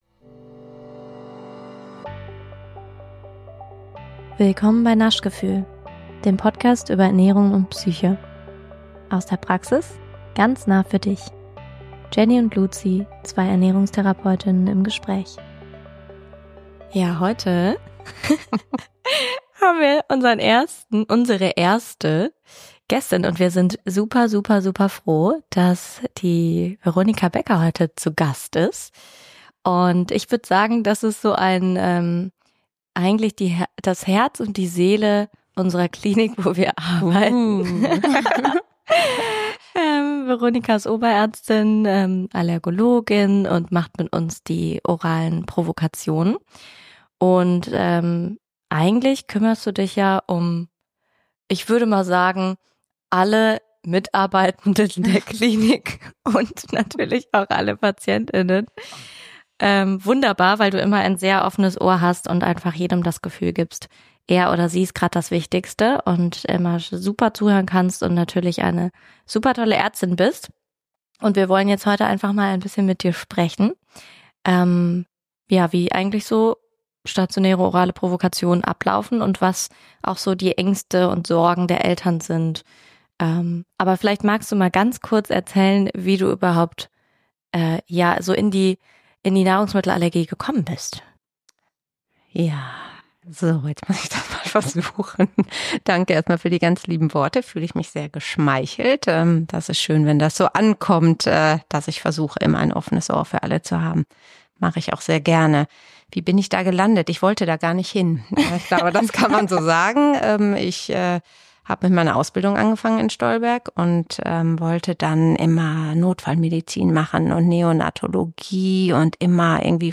Ein ganz tolles Gespräch, nicht nur für Betroffene!